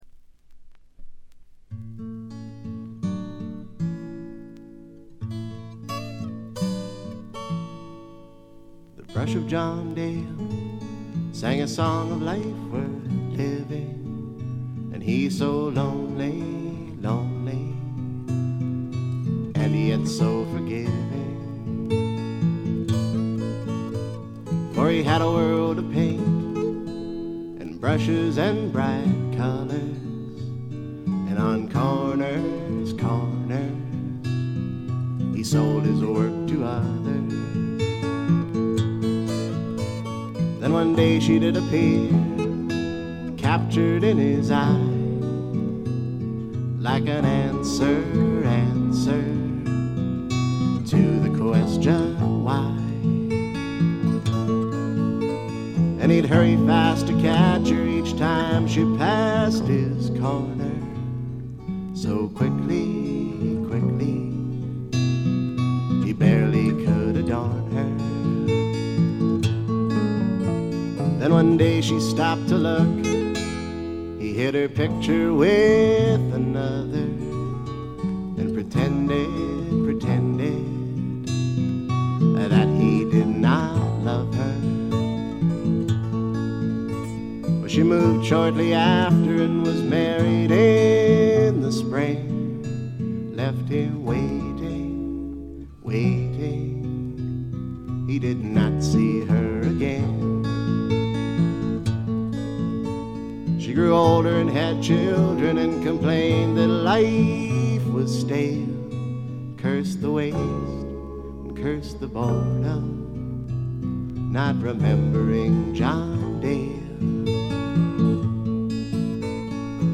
ごくわずかなノイズ感のみ。
カナディアン・シンガー・ソングライターの名盤。
試聴曲は現品からの取り込み音源です。
acoustic guitar